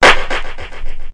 Clap (3).wav